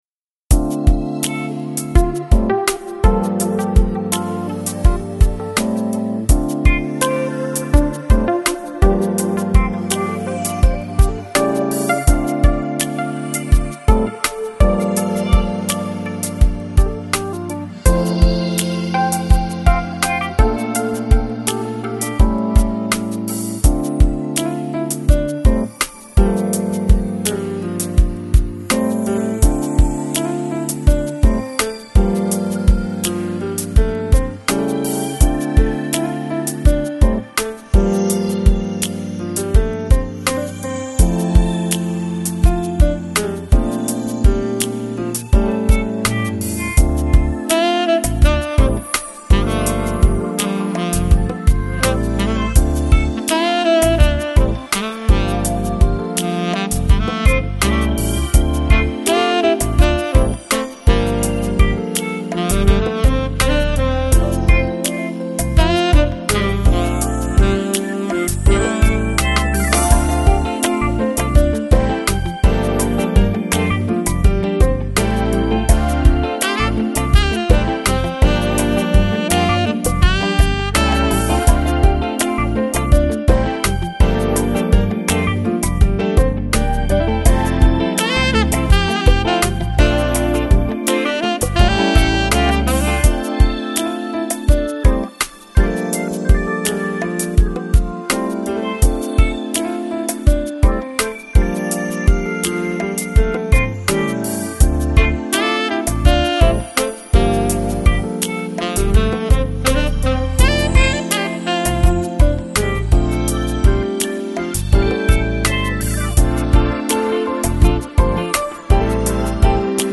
Lounge, Chill Out, Smooth Jazz